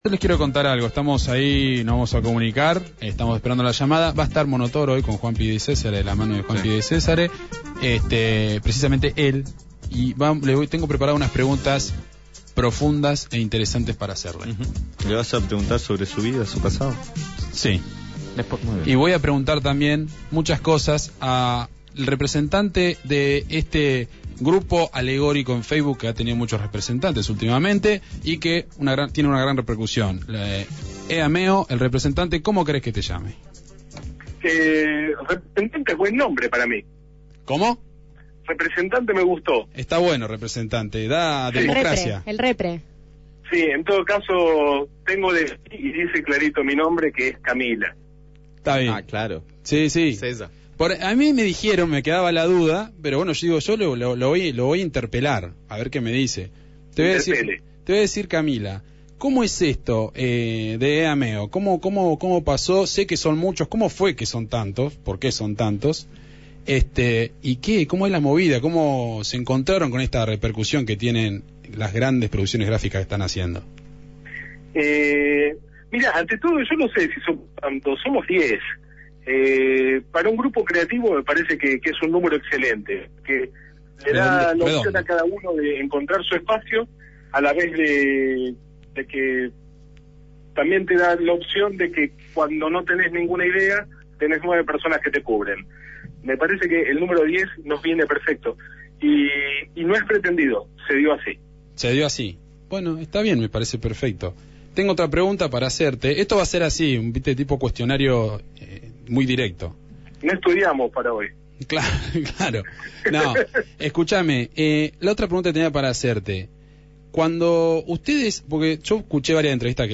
En MAM Latinoamérica entrevistaron a uno de los hacedores de EAMEO (con reserva de su identidad), quién comentó: «no somos muchos.